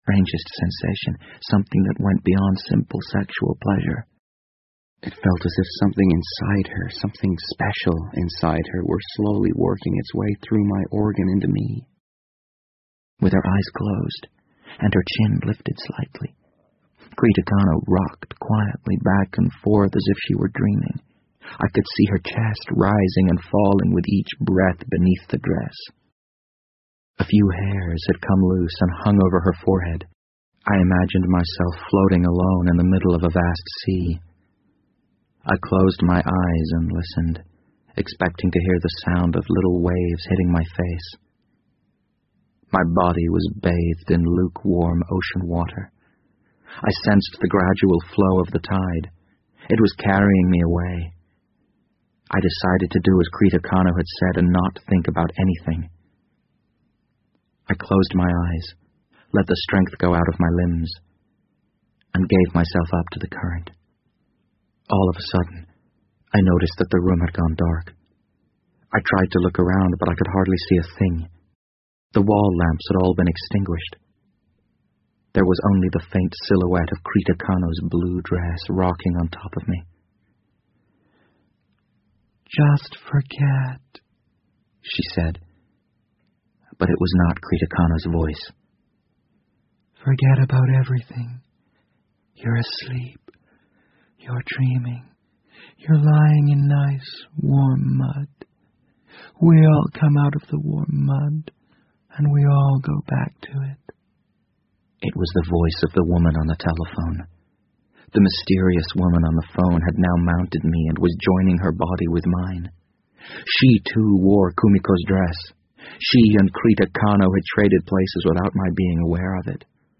BBC英文广播剧在线听 The Wind Up Bird 005 - 15 听力文件下载—在线英语听力室